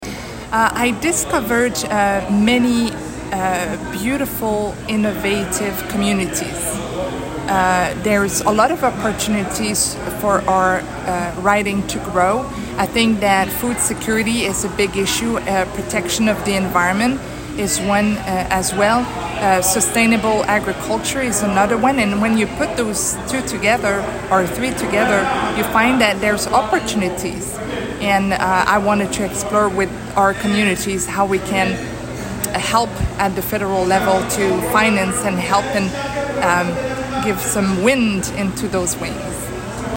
Following her commanding win, Chatel spoke with CHIP 101.9 about the campaign and her next steps as the region’s representative.